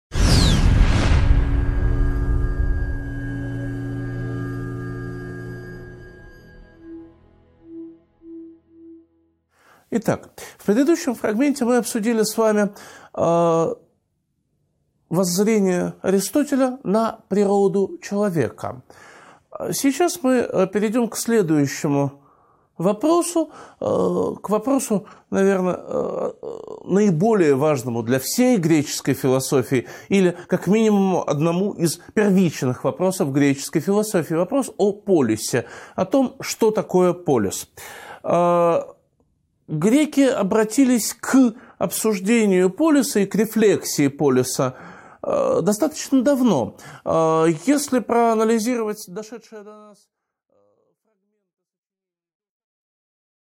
Аудиокнига 5.2 Понятие полиса | Библиотека аудиокниг